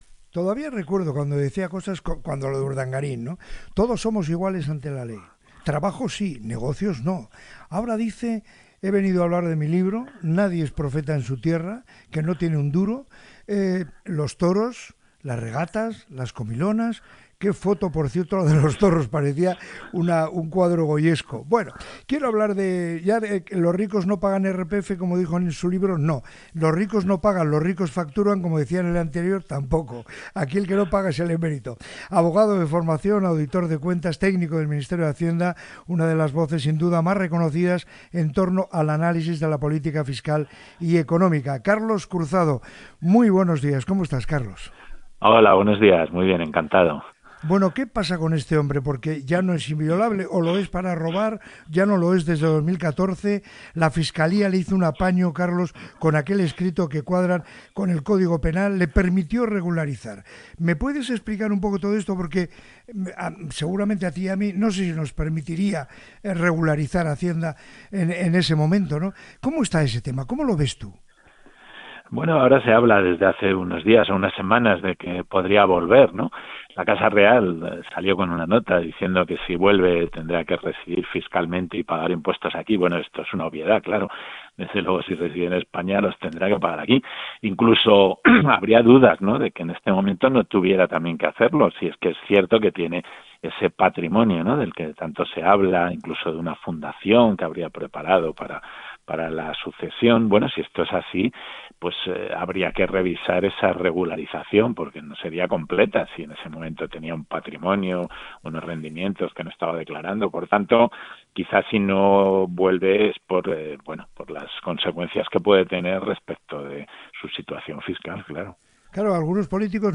Los técnicos de Hacienda advierten en Radio Popular sobre la fortuna oculta del monarca